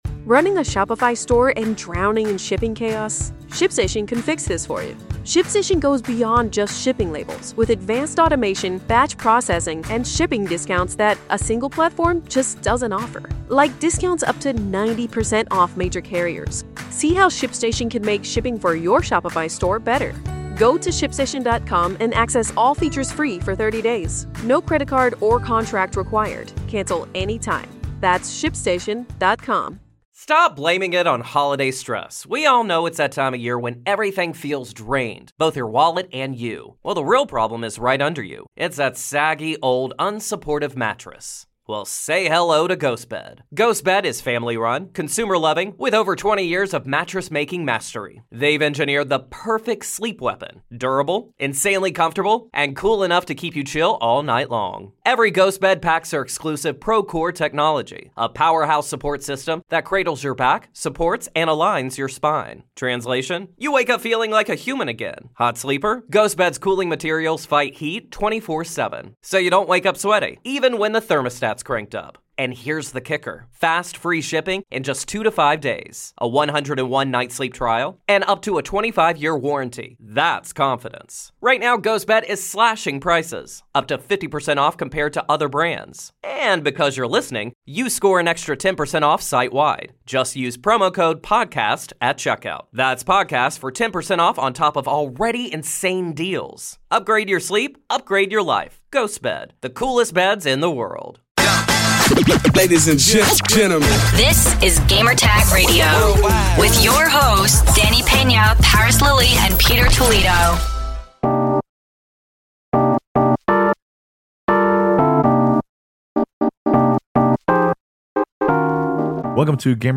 This week on Gamertag Radio, we have an interview with the dev team behind Dune: Awakening.